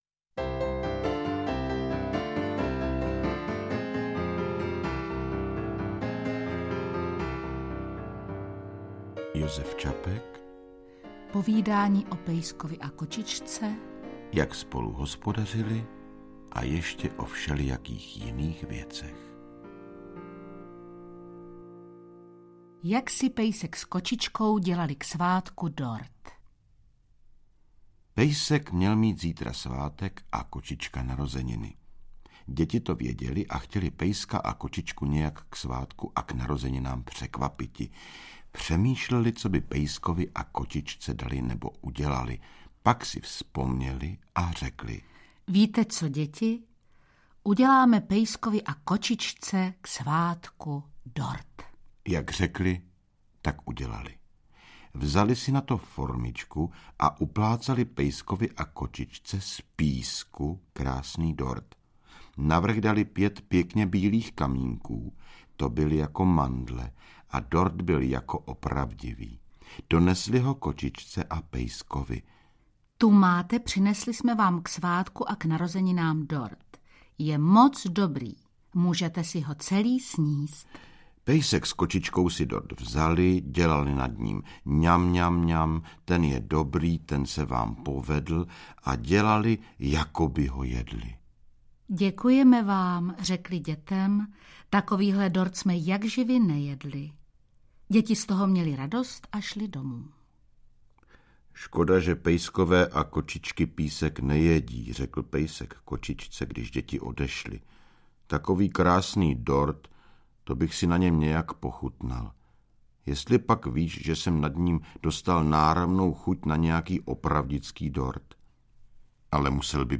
Povídání o pejskovi a kočičce (Jak spolu hospodařili a ještě o všelijakých jiných věcech) - Josef Čapek - Audiokniha
• Čte: Tomáš Töpfer, Dagmar Havlová-Veškrnová